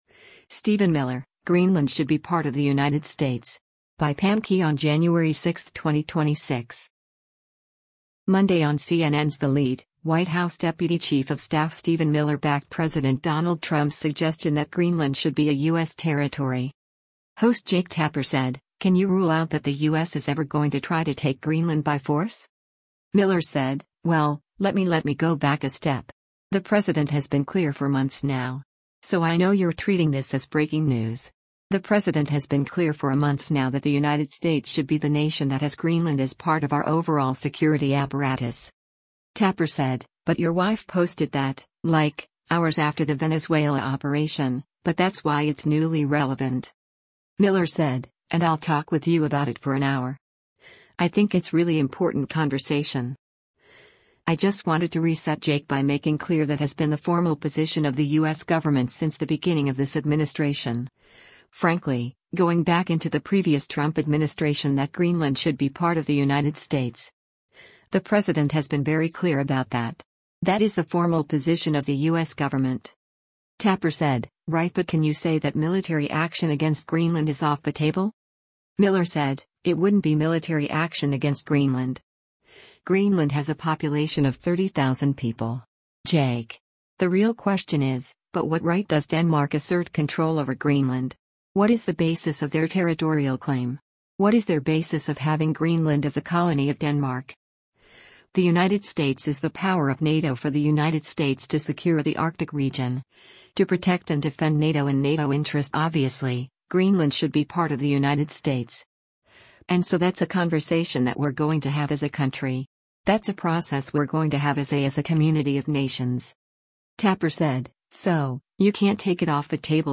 Monday on CNN’s “The Lead,” White House deputy chief of staff Stephen Miller backed President Donald Trump’s suggestion that Greenland should be a U.S. territory.